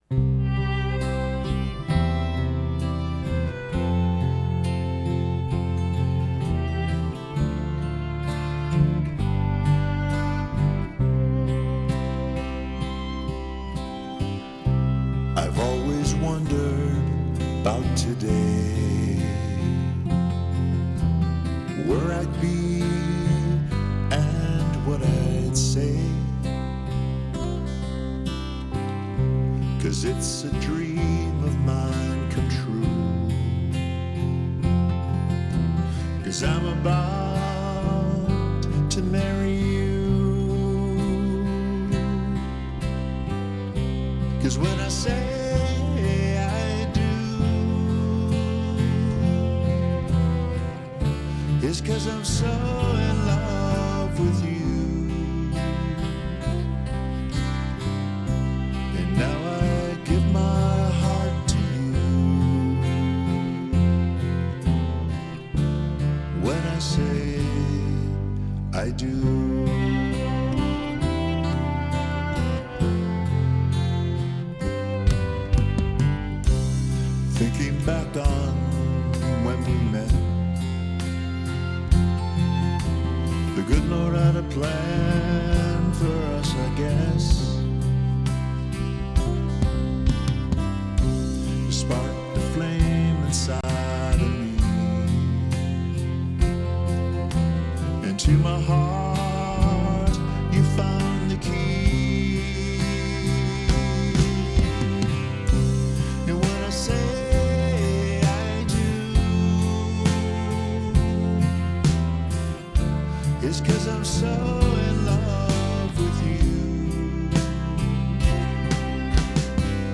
Key of C - Track with Reference Vocal